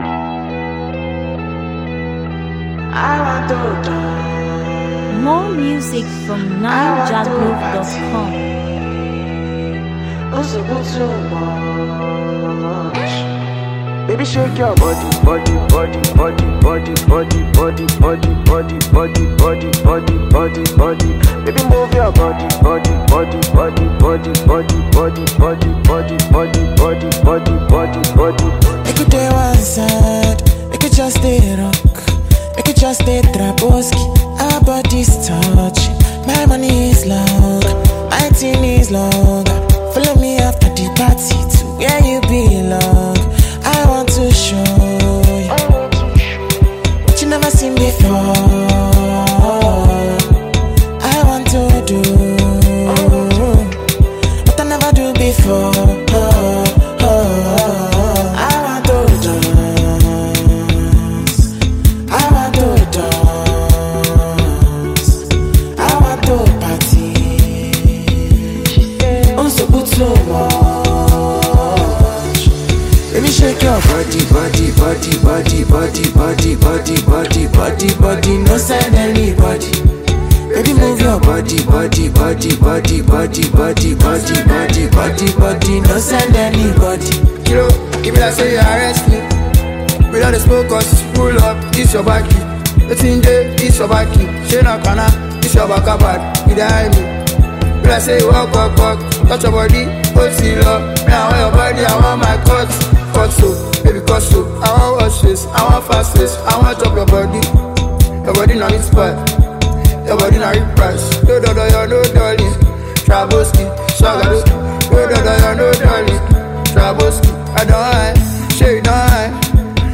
Latest, Naija-music